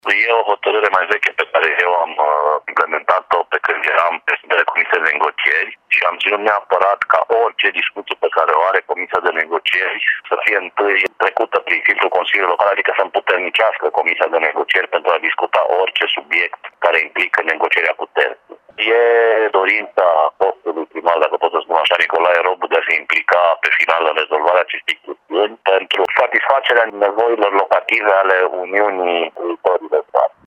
Acordul de principiu a fost necesar pentru a păstra actualul sediu, spune consilierul Adrian Orza.